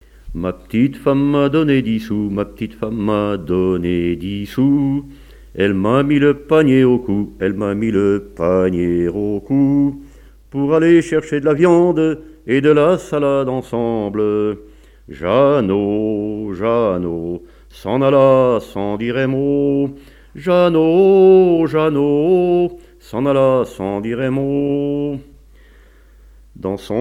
Genre strophique
Chansons et témoignages sur le chanson et la musique
Pièce musicale inédite